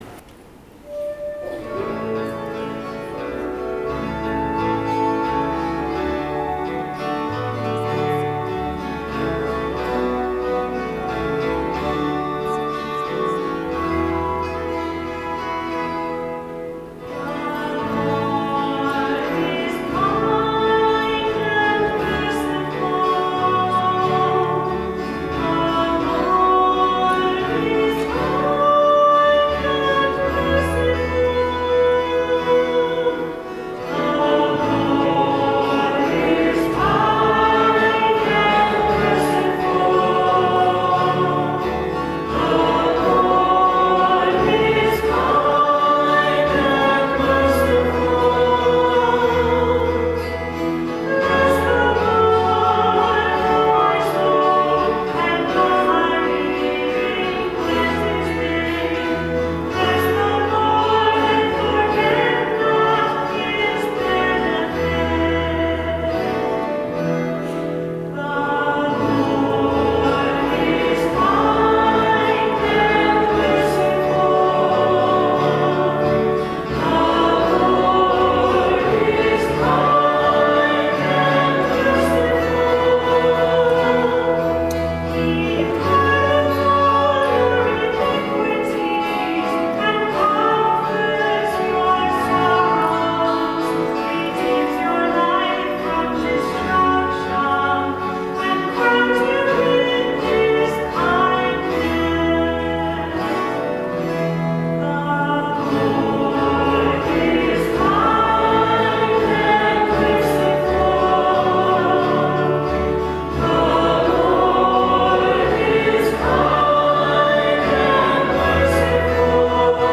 Music from the 10:30 Mass on the 1st Sunday in Lent, March 3, 2013:
Psalm: The Lord Is Kind and Merciful The Lord Is Kind and Merciful.mp3